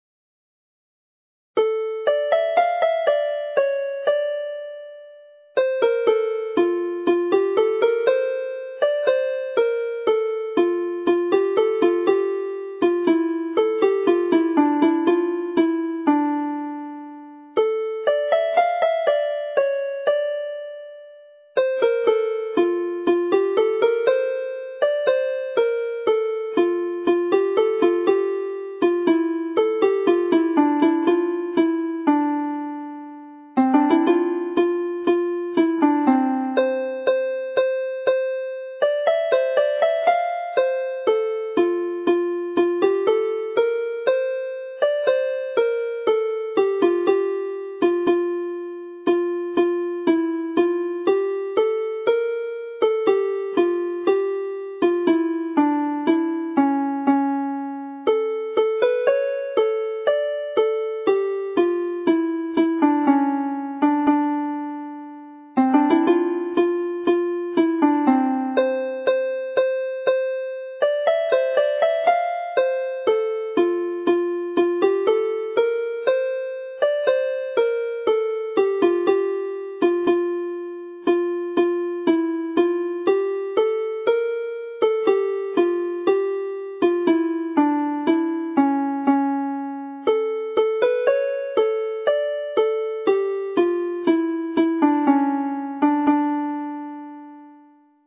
Alawon Cymreig - Set Sawdl y Fuwch - Welsh folk tunes to play -